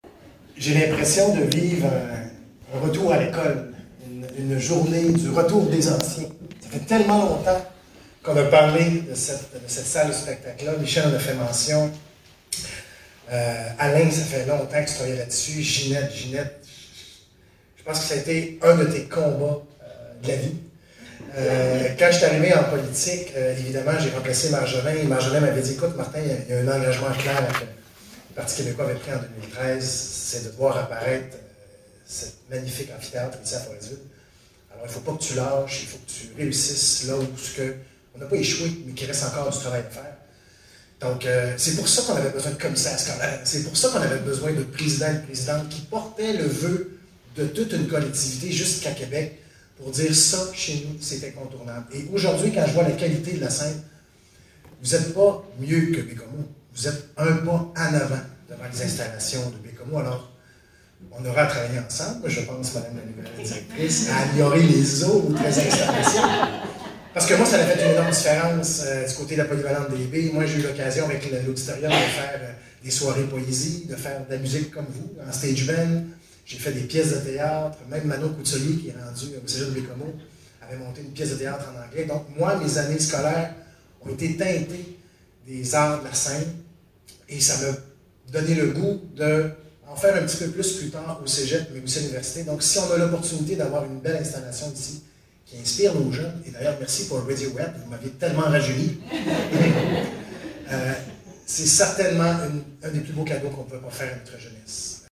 Lundi 27 septembre dernier avait lieu l’inauguration du Pavillon des Arts de Forestville.
Monsieur Martin Ouellet, Député de René-Lévesque était tout aussi fier de la concrétisation du projet en plus du sentiment de nostalgie qui l'habitait:
Martin-Ouellet-Innauguration.mp3